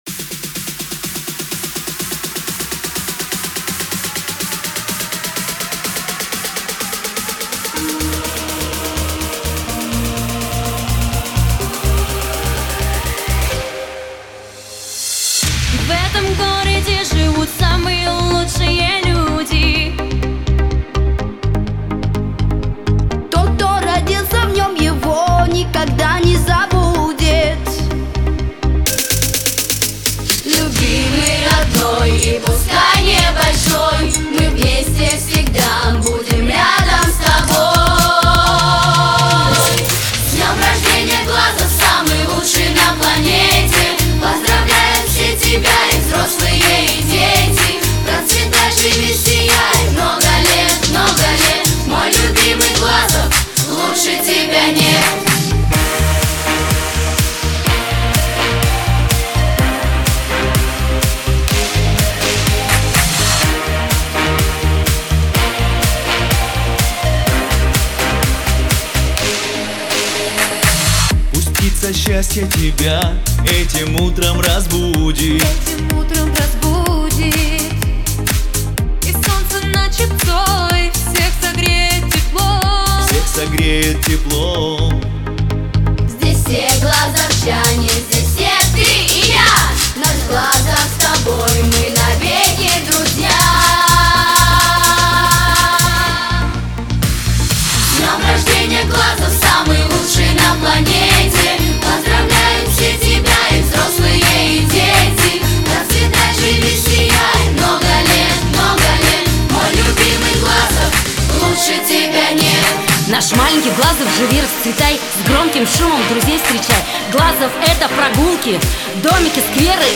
Песню представили на церемонии открытия Дня города. Ее исполнили юные глазовчане — участники детского вокального коллектива «Крылья» и солисты молодежного коллектива «Эстрадно-постановочное объединение» КЦ «Россия».